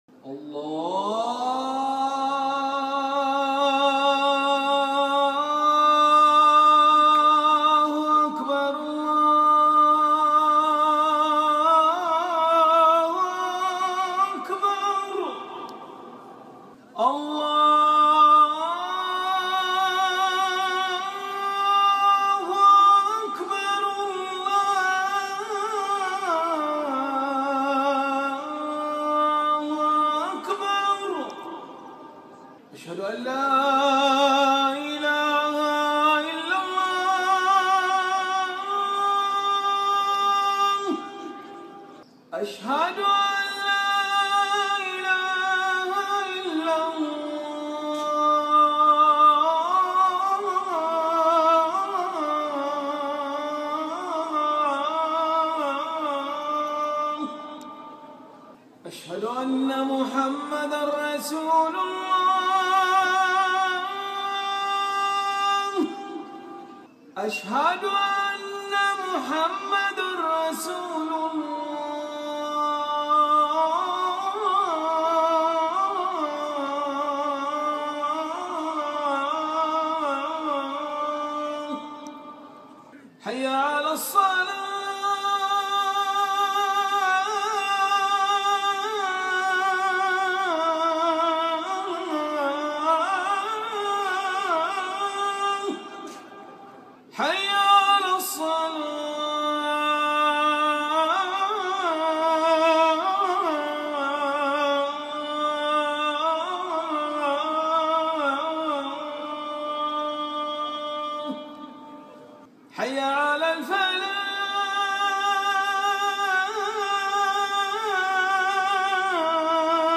আজান – Azan
আজান-Azan.mp3